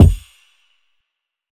TM88 ClassicKick.wav